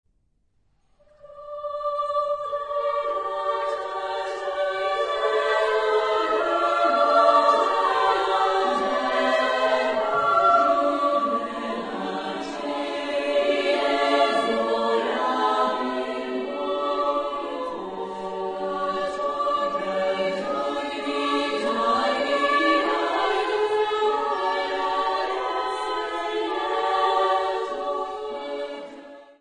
Genre-Style-Form: Madrigal ; Secular ; Renaissance
Type of Choir: SSSSA OR TTTTB  (5 men OR women voices )
Tonality: dorian
Discographic ref. : Internationaler Kammerchor Wettbewerb Marktoberdorf 2007